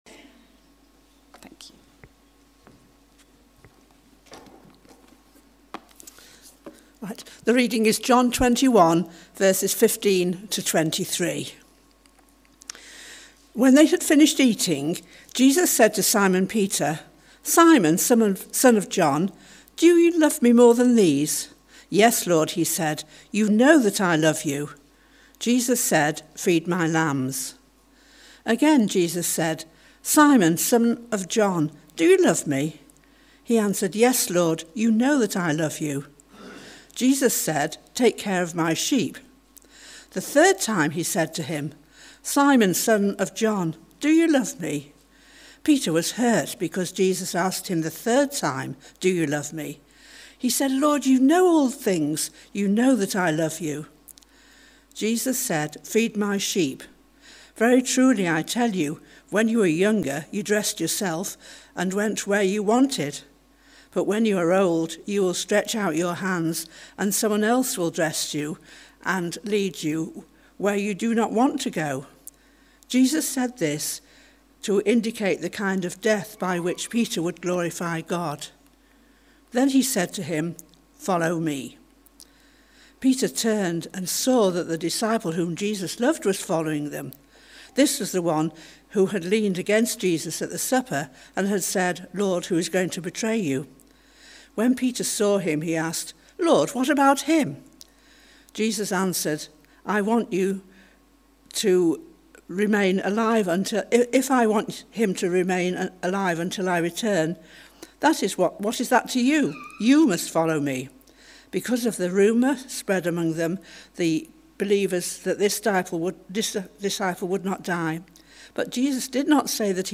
A talk from the series "Encounters with Jesus."